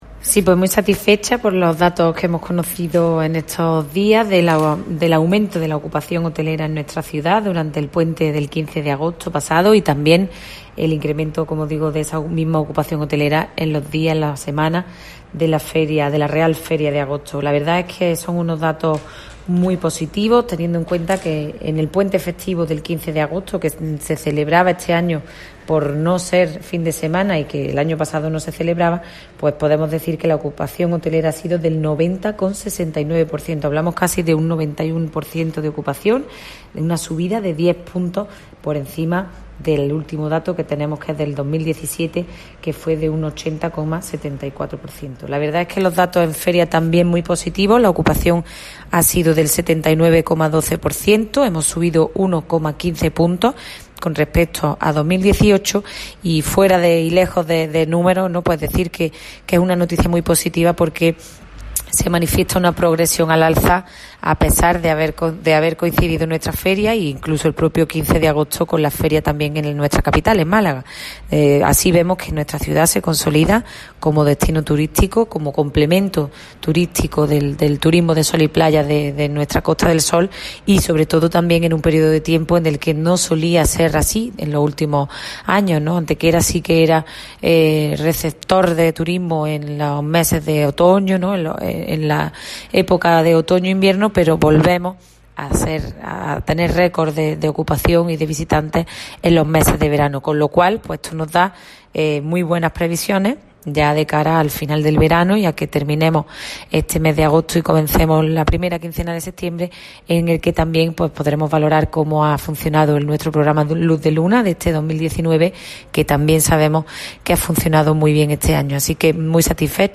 La teniente de alcalde delegada de Turismo, Ana Cebrián, informa sobre los datos relativos a la ocupación hotelera en nuestra ciudad durante dos de los principales períodos del verano en lo que a afluencia turística en nuestra ciudad se refiere.
Cortes de voz